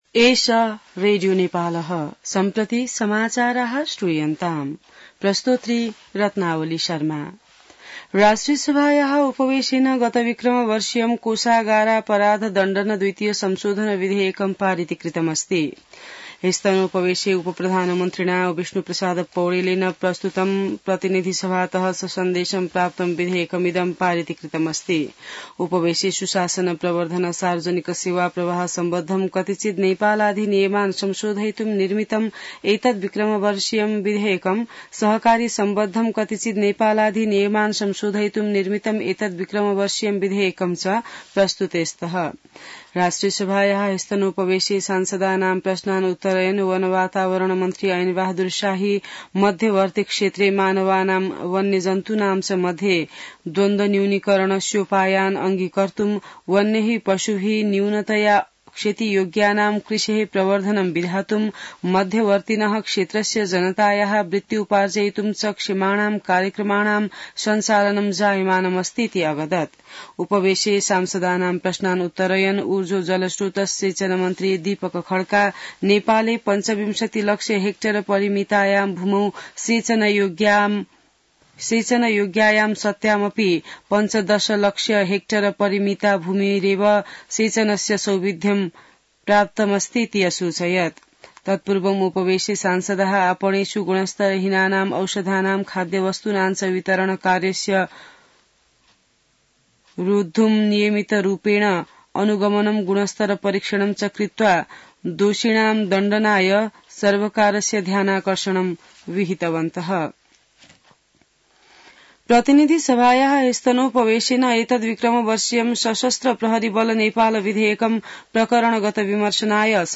संस्कृत समाचार : ७ चैत , २०८१